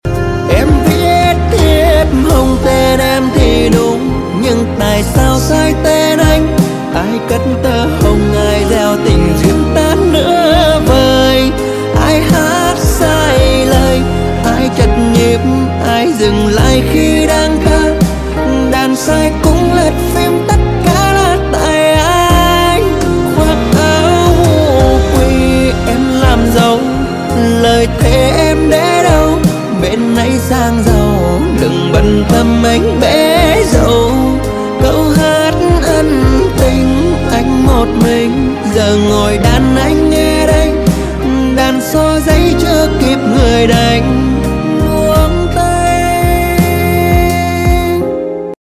Giai Điệu Bolero Remix Trữ Tình Đầy Day Dứt